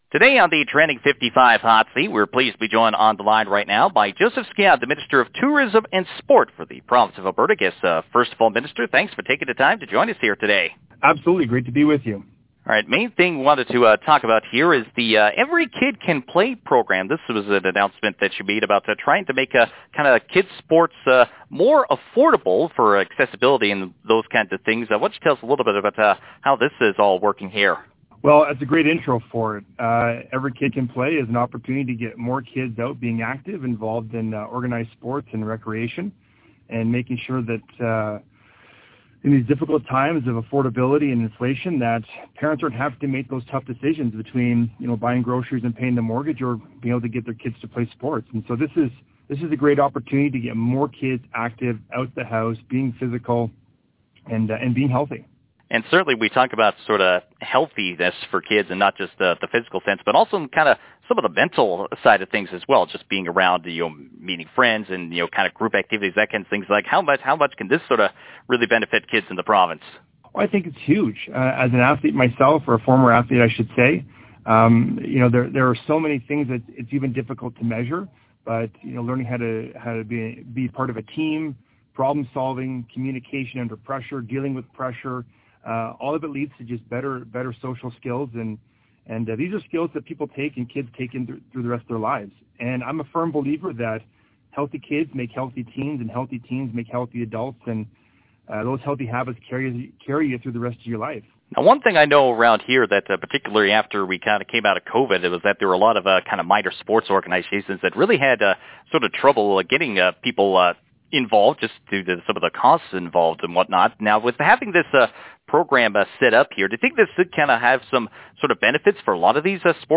The full interview with the minister can be found below.